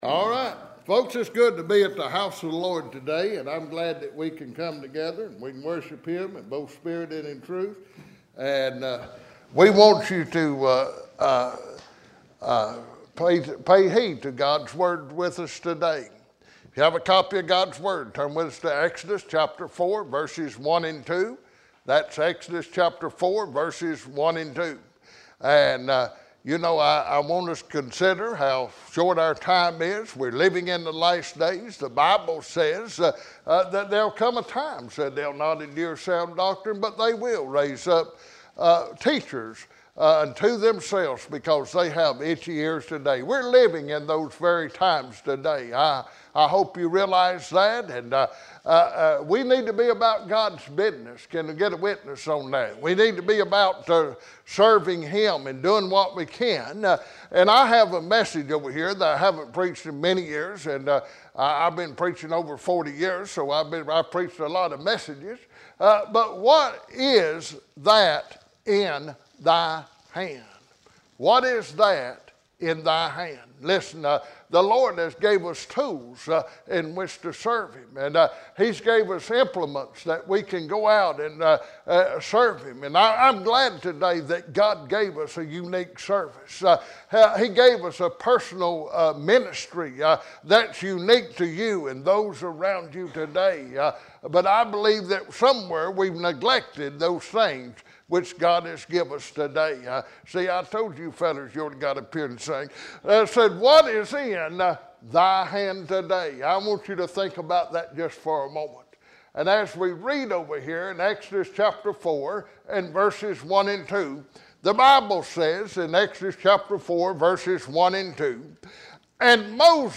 Bible Text: Exodus 4:1-2 | Preacher